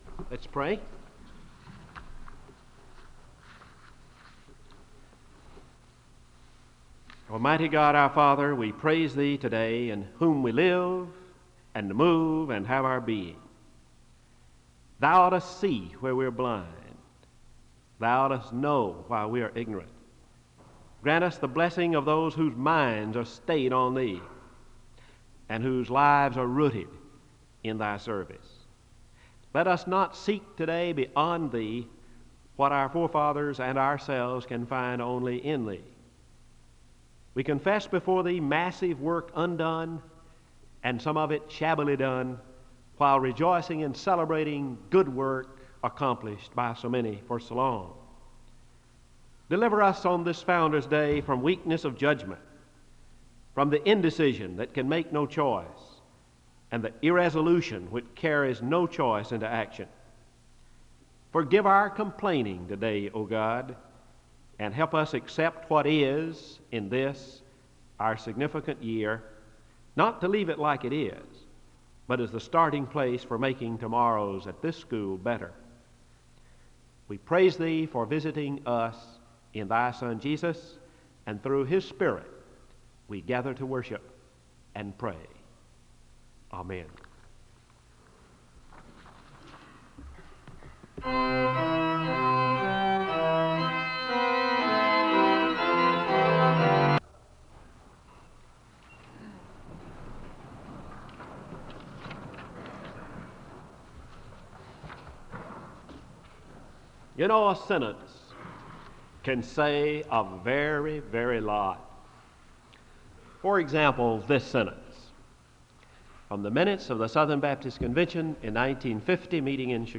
The service begins with a word of prayer (00:00-01:28).
The choir sings a song of worship (09:27-14:15).